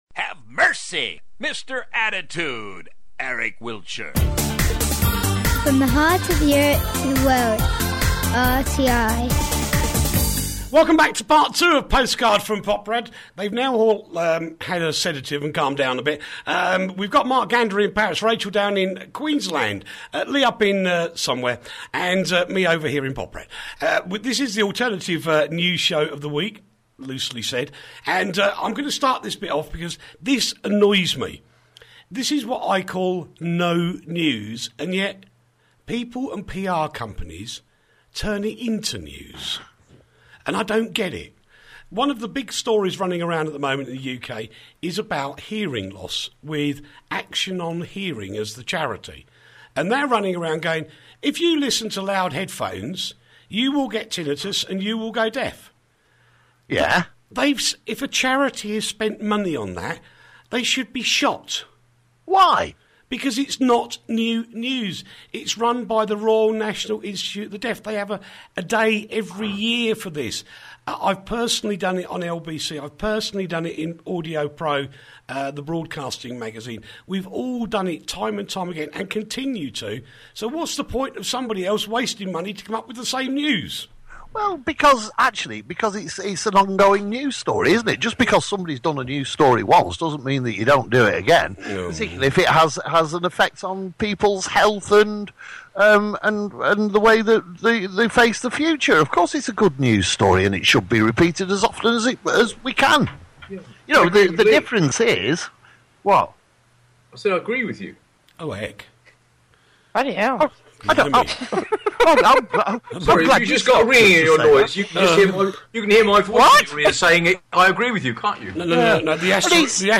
Postcard From Poprad the alternative news show from Radio Tatras International